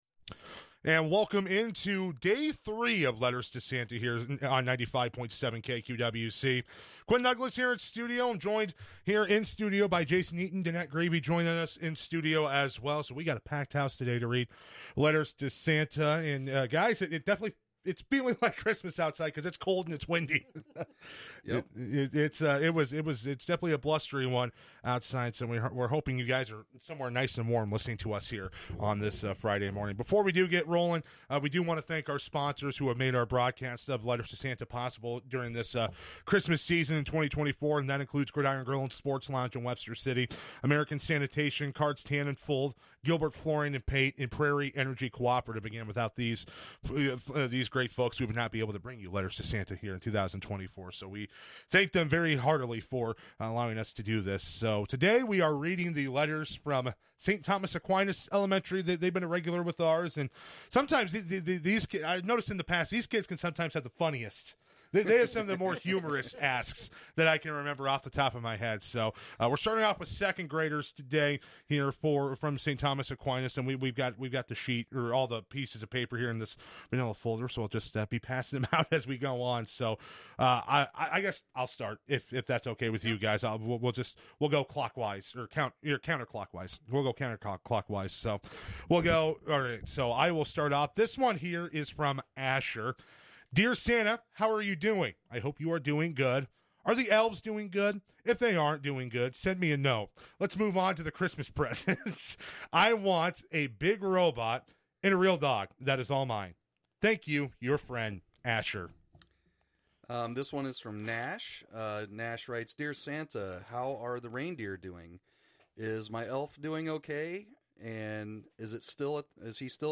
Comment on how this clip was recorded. Listen to a replay of our Letters to Santa broadcast from December 20th, 2024, reading letters from St. Thomas Aquinas Elementary School in Webster City